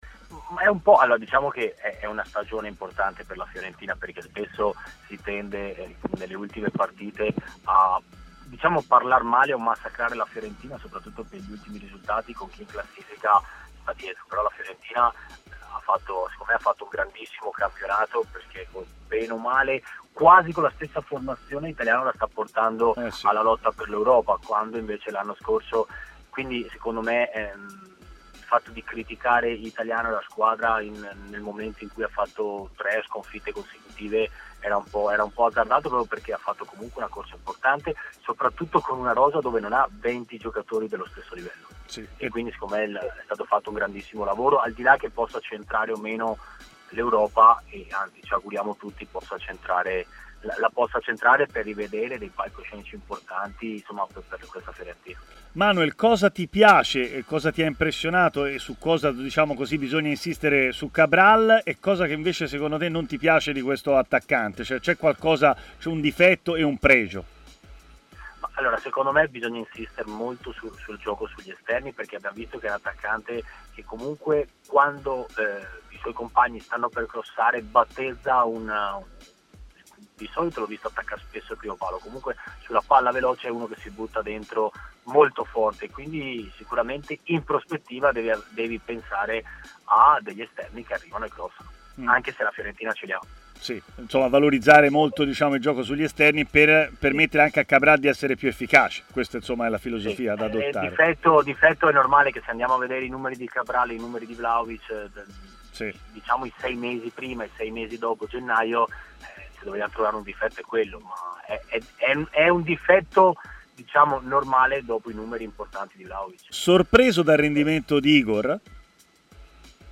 L'ex difensore della Fiorentina Manuel Pasqual, oggi commentatore tv, ha così parlato durante Stadio Aperto, trasmissione di TMW Radio, della Fiorentina: "La stagione è stata grandissima, quasi con la stessa formazione dell'anno scorso Italiano li sta facendo lottare per l'Europa.